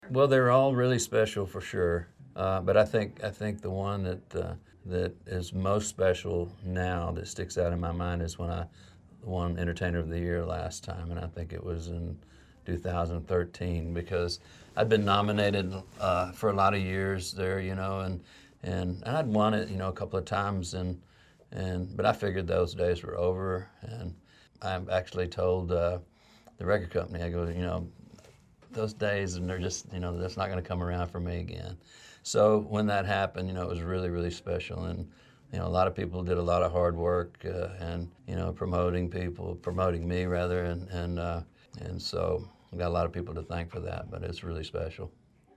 Audio / George Strait says his most special CMA Award is his Entertainer of the Year win in 2013.